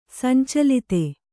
♪ sancalite